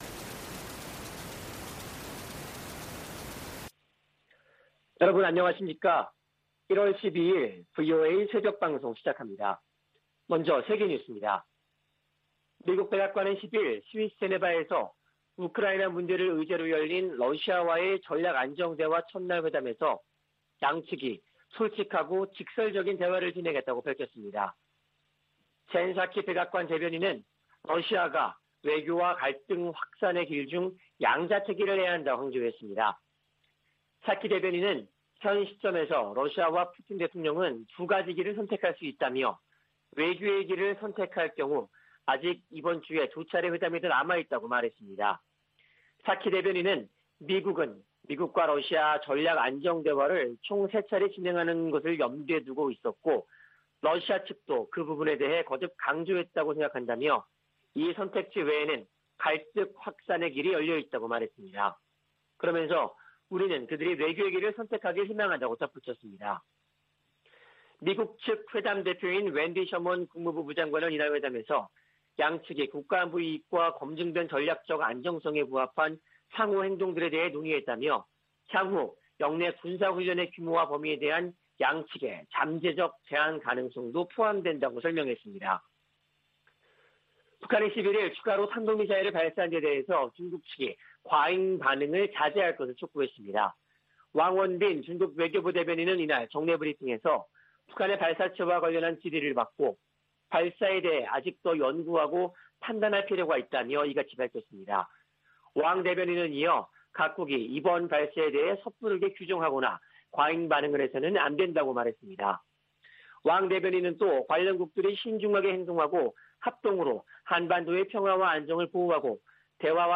VOA 한국어 '출발 뉴스 쇼', 2021년 1월 12일 방송입니다. 북한이 엿새 만에 또 다시 탄도미사일 추정체를 발사했습니다. 유엔 안보리가 지난 5일의 북한 탄도미사일 발사 문제를 논의했습니다. 미 국방부는 극초음속 미사일을 발사했다는 북한의 주장에 세부사항을 평가 중이라고 밝혔습니다.